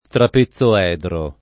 trapezoedro [ trape ZZ o $ dro ] s. m.